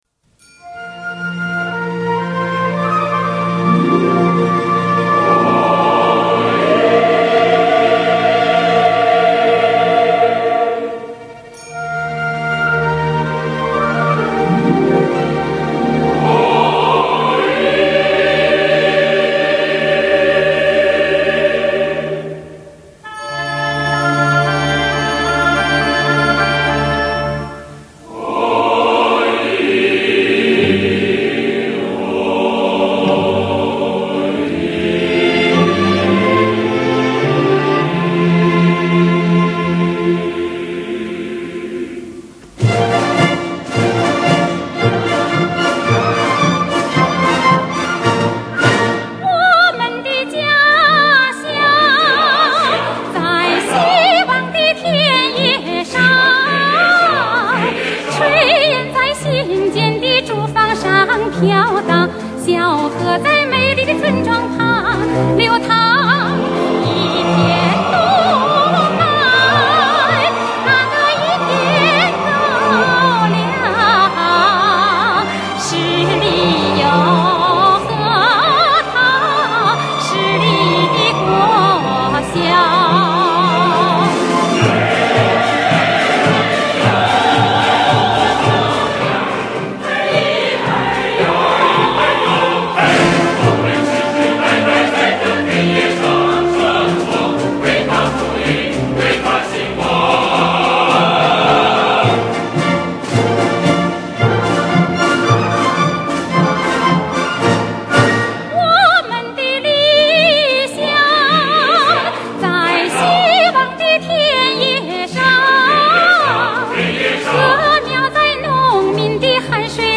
本人也觉得合唱的这首歌非常好听，很大气，而且应该是首唱，反正我第一次听到这首歌就是这个版本。